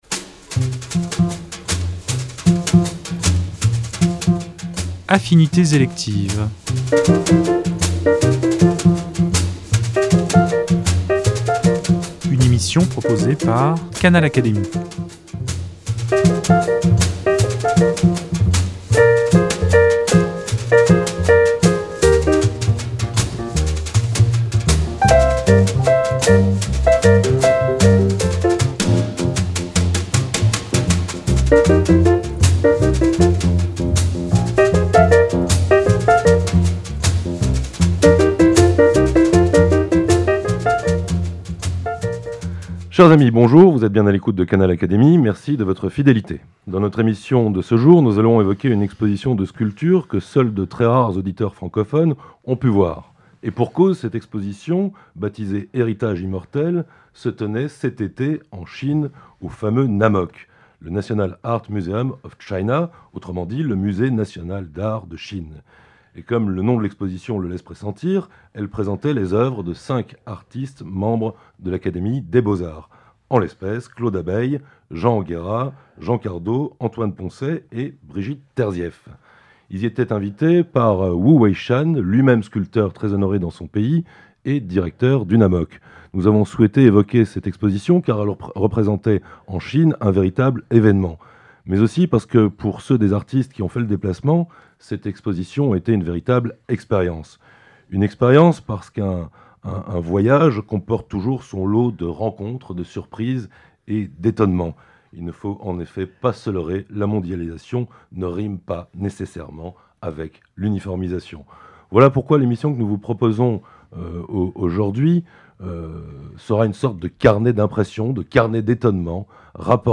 Leur libre conversation ne se limite toutefois pas à cet événement.